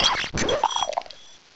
sovereignx/sound/direct_sound_samples/cries/poltchageist.aif at master